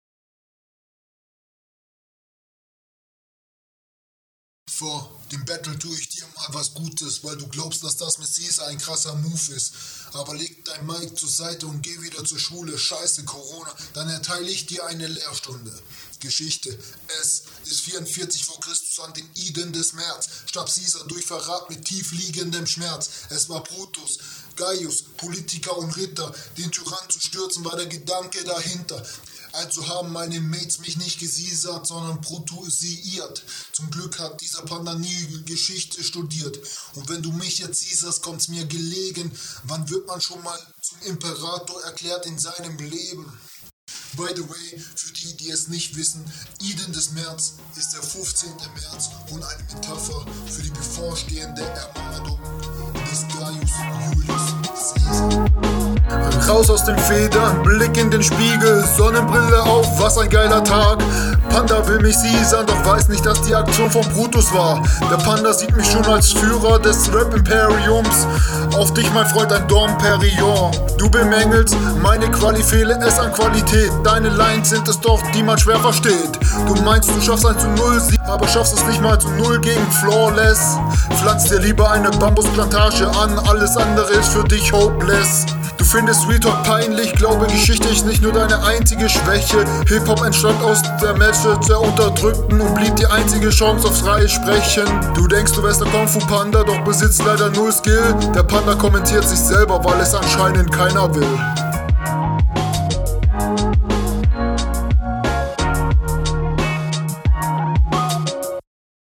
Flow a capella war tatsächlich besser als auf dem Beat.